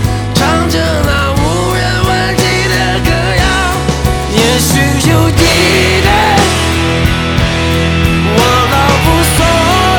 rock_cn.wav